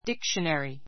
díkʃəneri ディ ク ショネリ ｜ díkʃənəri ディ ク ショナリ